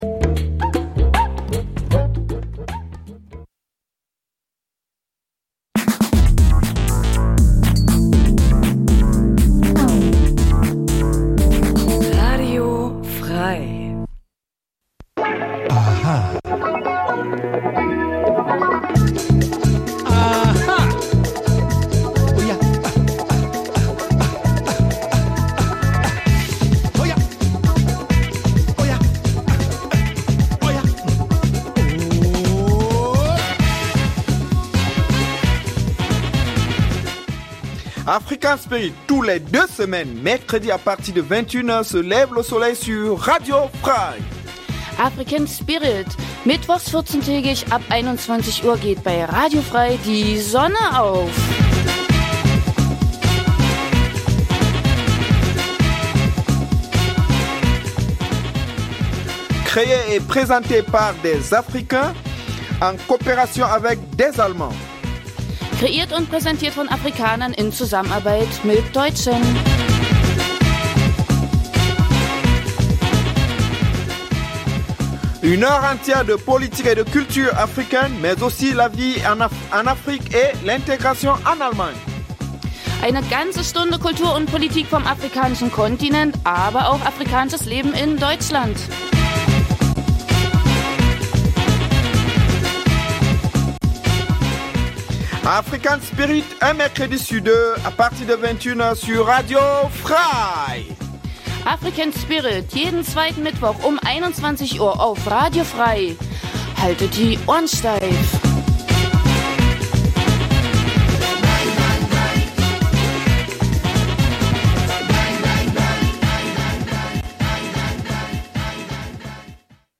Speziell versuchen wir die Berührungspunkte zwischen Afrika und Thüringen zu beleuchten. In einem Nachrichtenblock informiert die Sendung über die aktuelle politische Situation vor allem aus Afrikas Krisengebieten, aber auch über die Entwicklung der Zuwanderungs-politik in Deutschland welche das Leben vieler Afrikaner bei uns beeinflußt. Neben aktuellen Nachrichten gibt es regelmäßig Studiogäste, Menschen die in irgendeiner Form etwas mit Afrika zu tun haben: Zum Beispiel in Thüringen lebende Afrikaner, die uns über ihr Heimatland berichtet aber auch davon welche Beziehungen sie zu Deutschland haben oder Deutsche die aus beruflichen Gründen in Afrika waren und uns über ihre Erfahrungen berichten. Die Gespräche werden mit afrikanischer Musik begleitet.